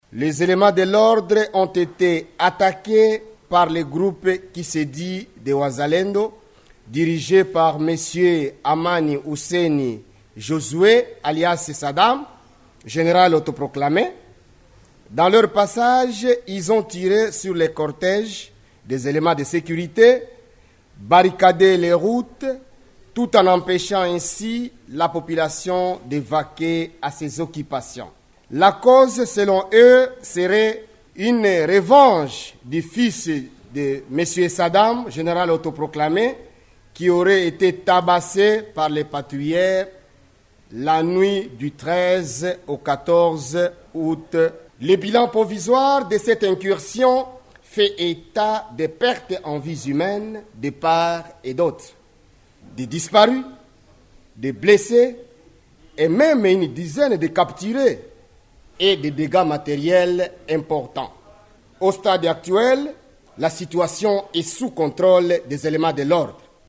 C'est ce qu'explique Taylor Lawamo Selemani, ministre provincial de l’Intérieur et de la sécurité: